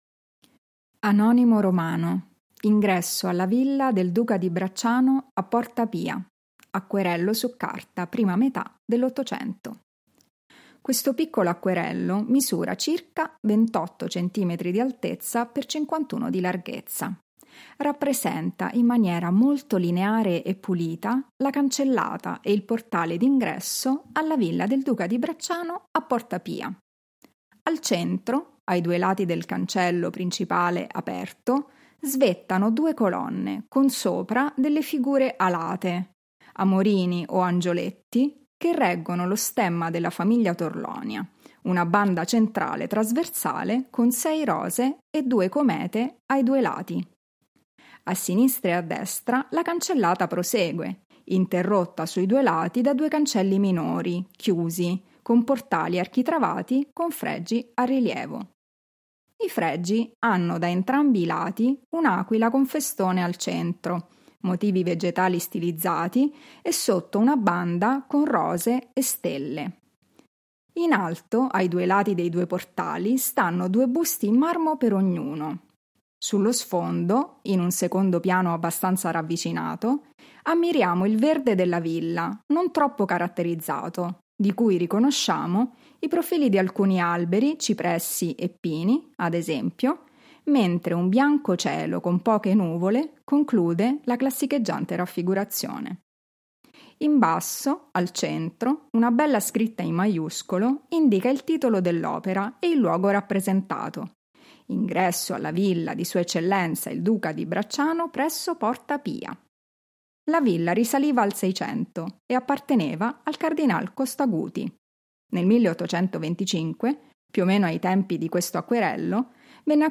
Audio-descrizioni sensoriali: